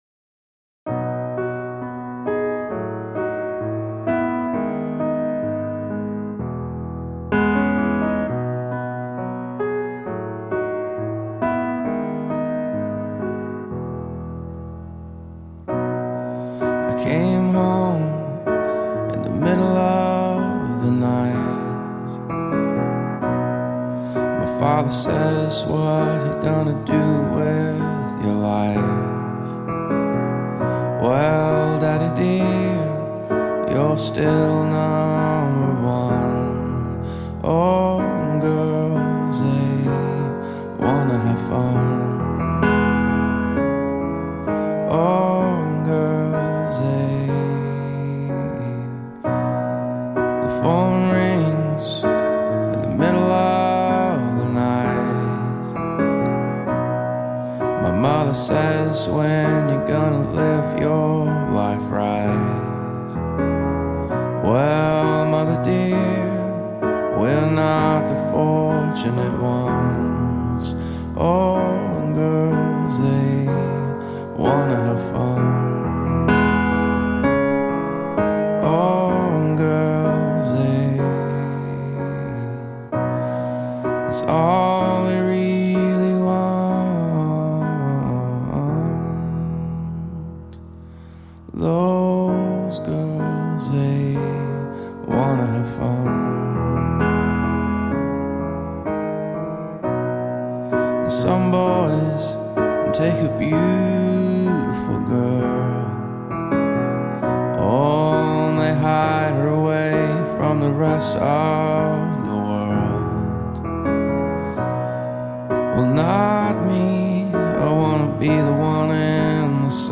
somber piano ballad
Yet there is nothing “campy” or “cheeky” in the delivery.